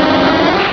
Cri de Noctali dans Pokémon Rubis et Saphir.